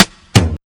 SNARE ROLL 2.wav